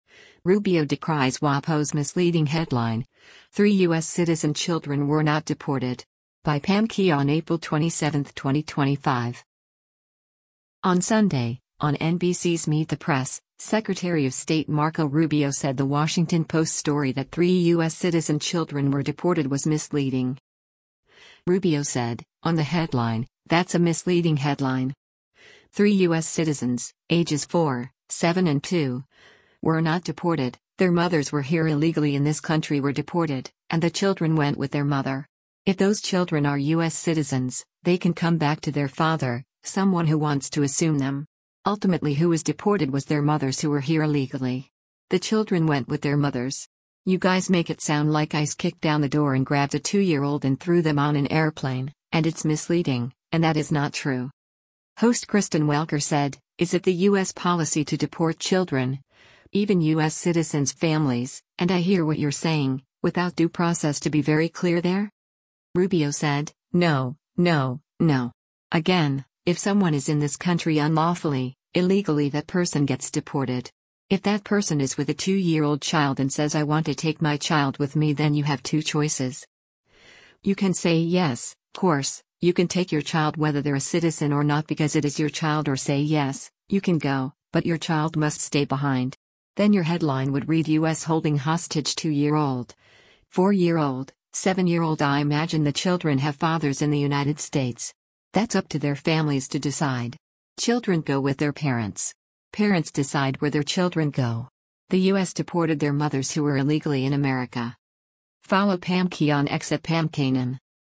On Sunday, on NBC’s “Meet the Press,” Secretary of State Marco Rubio said The Washington Post story that three U.S. citizen children were deported was “misleading.”